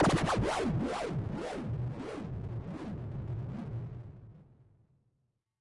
标签： cliche death effect falling fx helmut helmutscream scream shout shouting sound speech wilhelm wilhelmscream yell
声道立体声